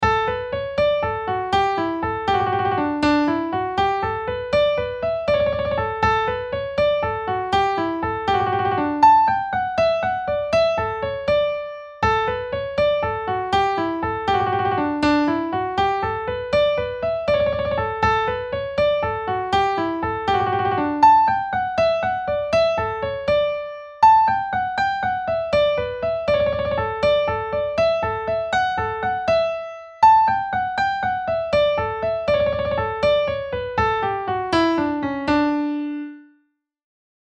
A jig, folk dance, was usually solo, and particularly popular in Scotland and northern England in the 16th and 17th centuries and in Ireland from the 18th century.
[This links to an MP3 file it's a very brief piano performance].